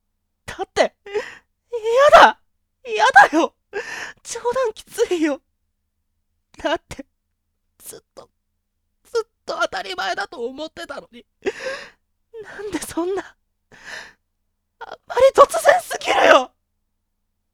・元気で明るく行動力がある
【サンプルボイス】